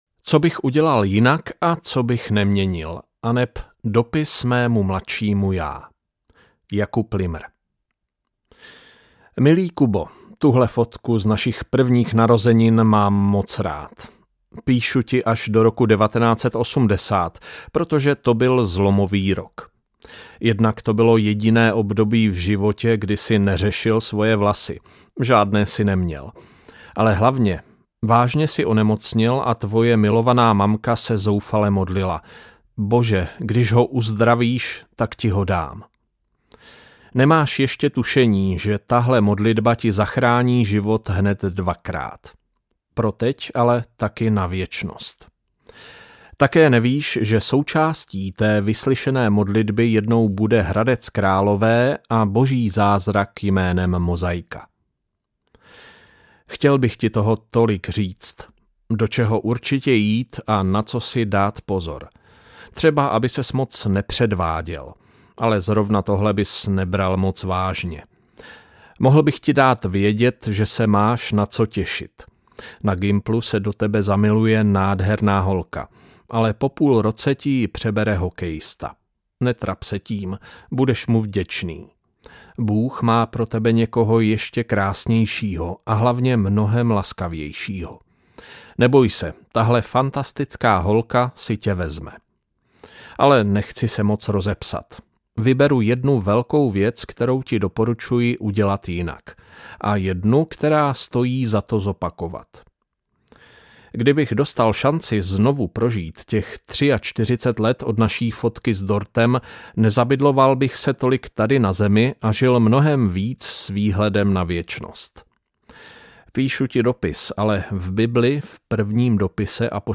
Nahráno ve studiu Rádia 7.